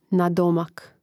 nadòmak nadomak